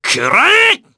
Gladi-Vox_Attack4_jp.wav